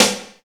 118 SNARE.wav